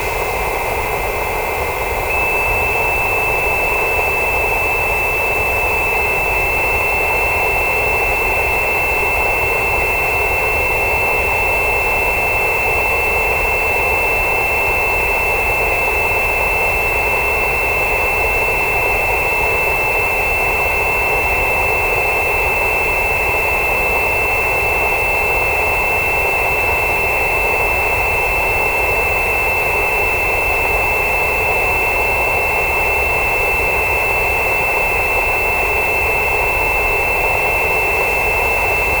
I recorded Mac Studio whistling at a sampling rate of 96000 Hz. I edited the sound, limiting the frequencies outside of 2000 Hz, about 2048 Hz and 2700 Hz I highlighted.
Despite all this, it's impossible to tell if it's the coils or the mechanical sound.